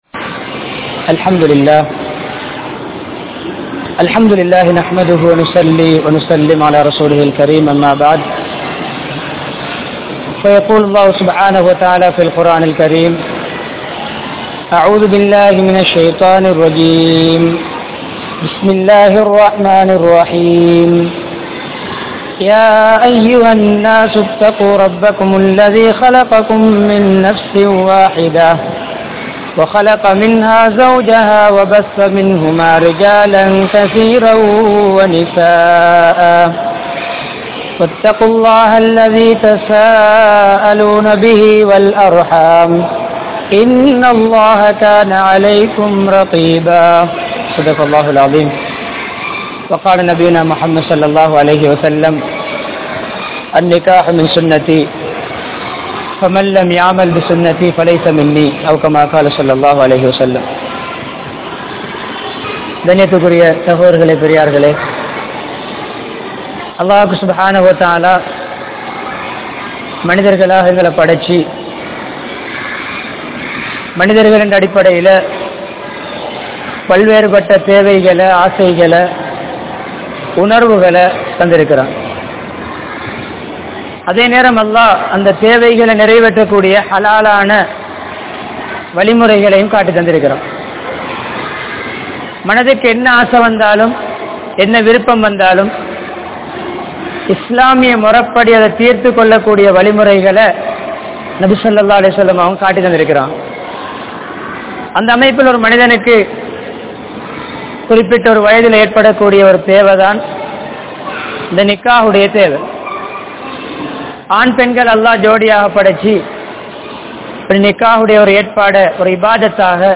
Islamiya Kudumba Vaalkai (இஸ்லாமிய குடும்ப வாழ்க்கை) | Audio Bayans | All Ceylon Muslim Youth Community | Addalaichenai
Wattala, Mabola, Arachiwatta, Rabbaniyah Jumua Masjidh